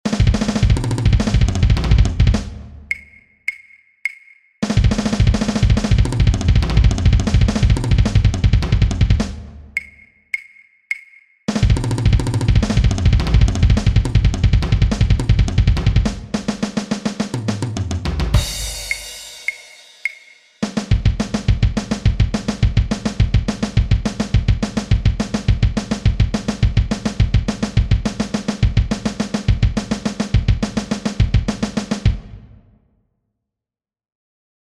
Schnelle Fill-Ins
Entweder du benutzt ein Doppelpedal für die Bass Drum, oder du nutzt die Doppelschlag-Technik (grob gesagt Spitze Hacke).
Schnelle Fill Ins Tempo 105.mp3
schnelle_fill_ins_tempo_105.mp3